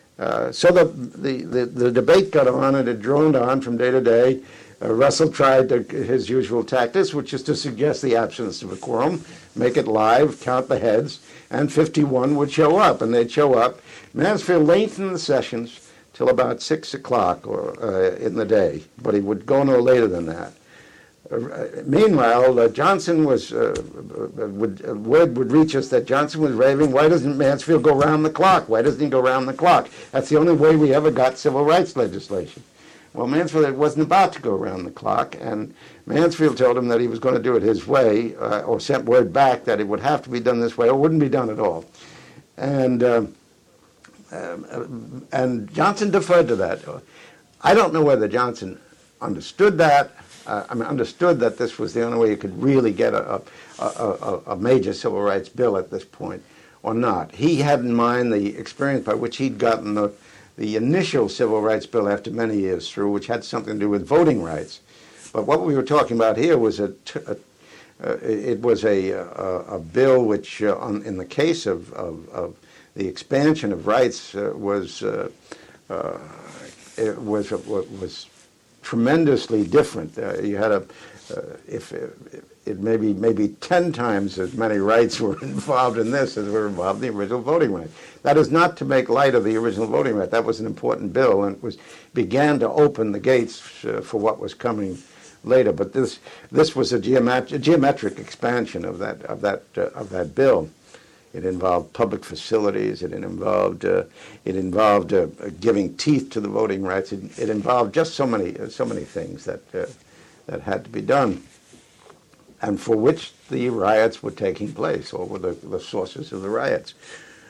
Oral History Project